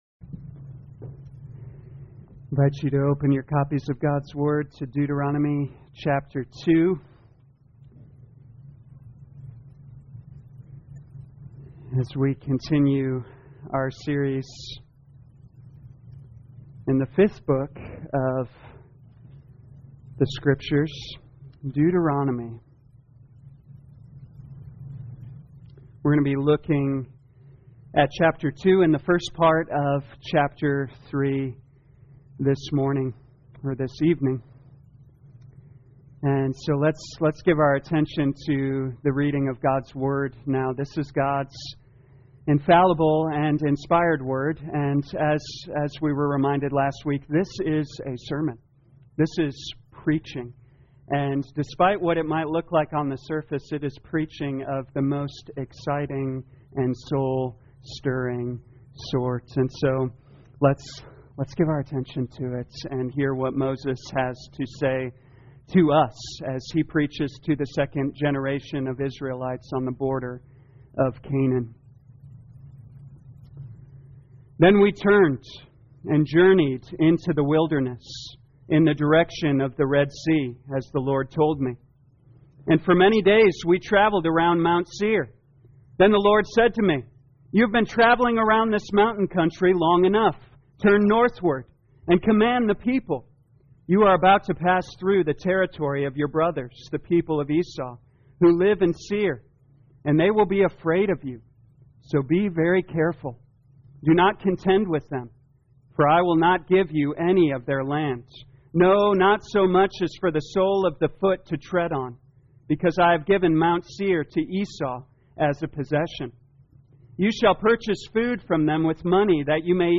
2021 Deuteronomy The Law Evening Service Download